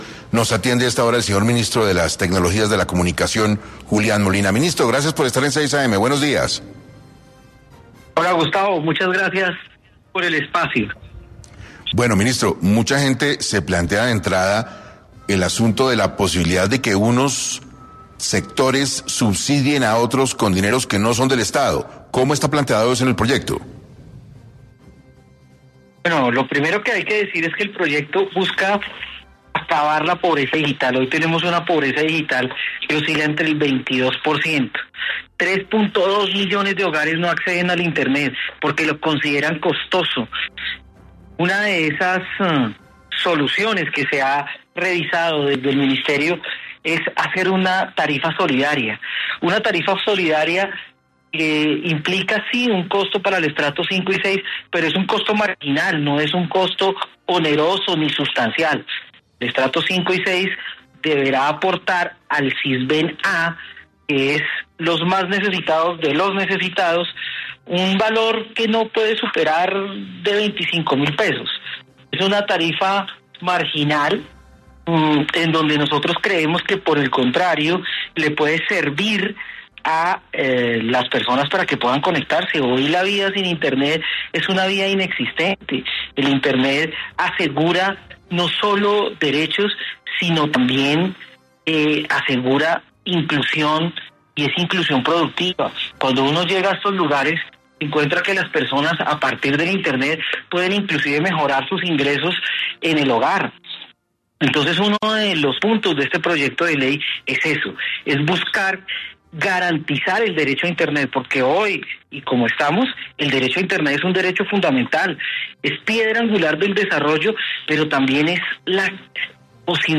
Julián Molina, ministro de las TIC, habló sobre el proyecto de ley de internet solidario en #6 AM de Caracol Radio